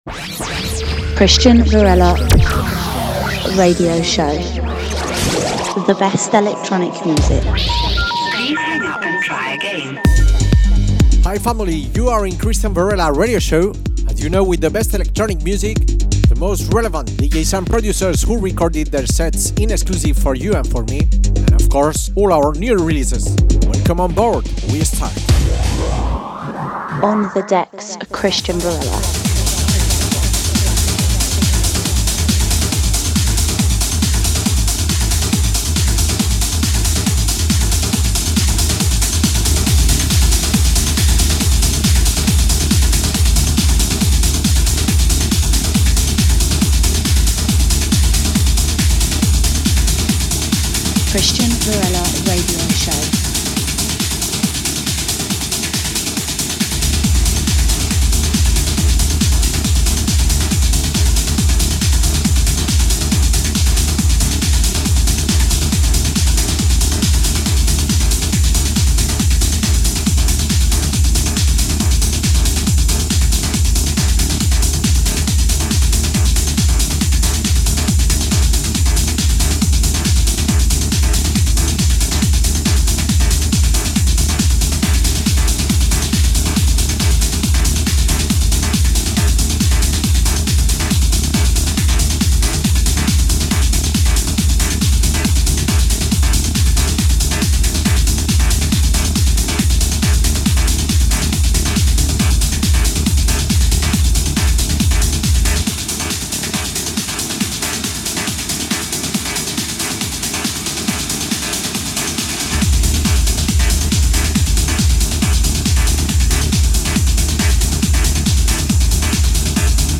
The Best Underground Techno!